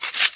shield_remove.WAV